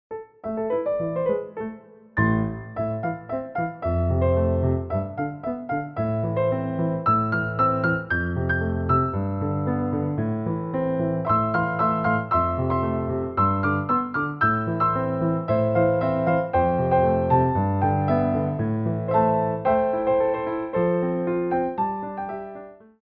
2/4 (8x8)